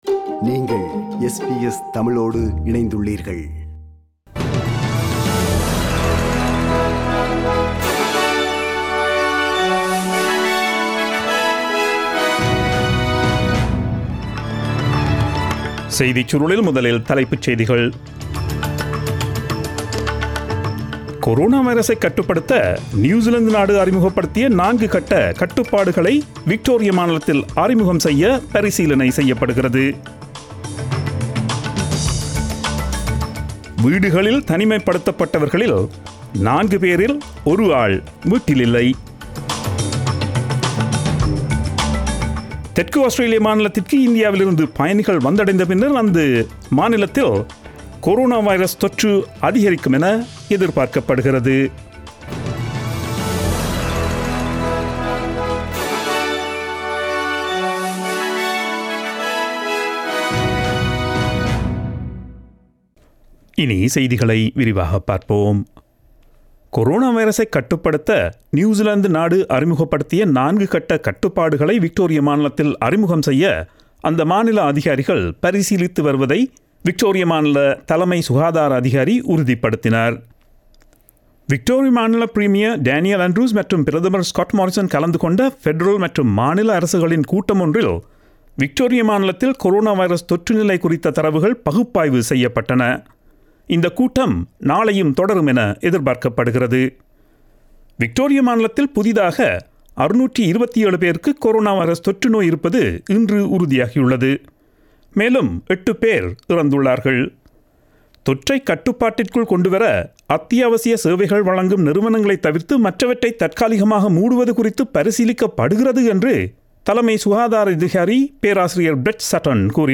Australian news bulletin aired on Friday 31 July 2020 at 8pm.